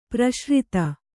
♪ praśrita